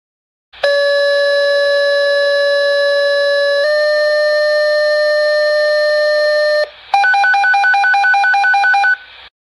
Fire Pager
fire-pager.mp3